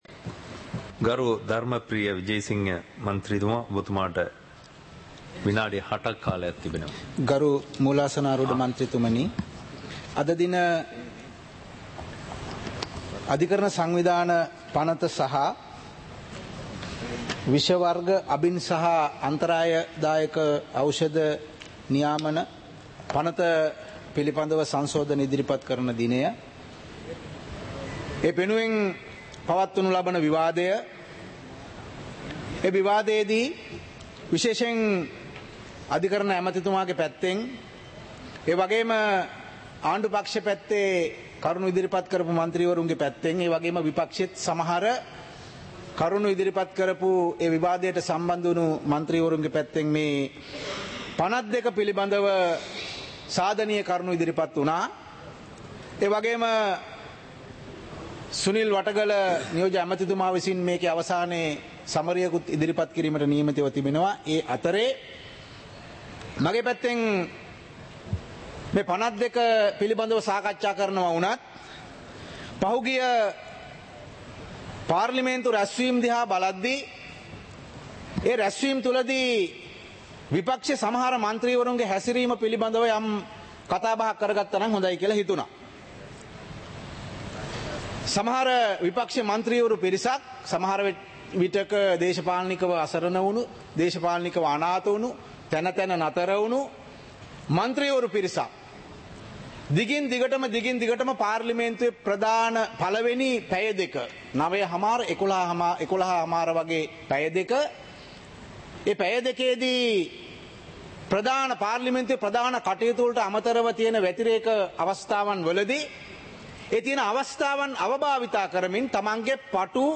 සභාවේ වැඩ කටයුතු (2026-02-19)
පාර්ලිමේන්තුව සජීවීව - පටිගත කළ